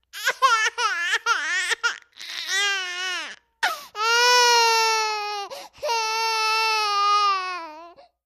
Baby: 10-month-old, Crying. Interior. Mono